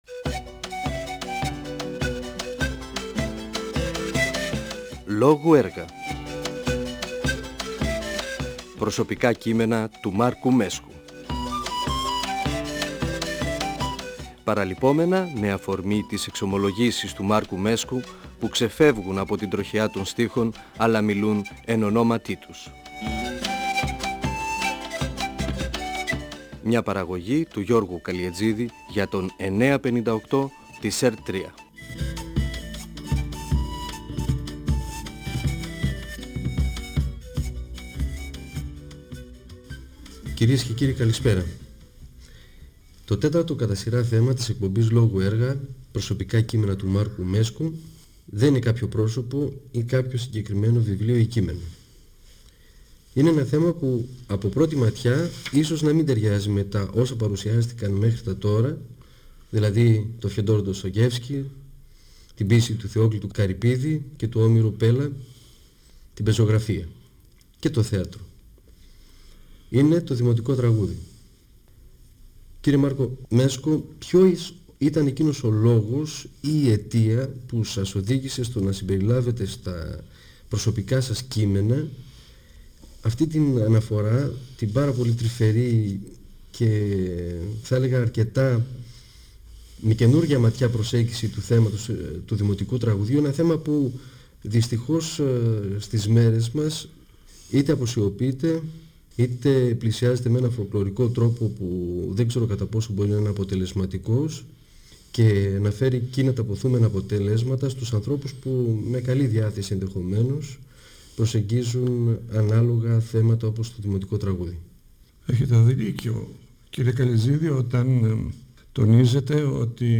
Αφορμή για τη συζήτηση με τον Μάρκο Μέσκο στάθηκε το βιβλίο του «Προσωπικά κείμενα» (εκδ. Νεφέλη, 2000).ΦΩΝΕΣ ΑΡΧΕΙΟΥ του 958fm της ΕΡΤ3.